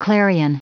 Prononciation du mot clarion en anglais (fichier audio)
Prononciation du mot : clarion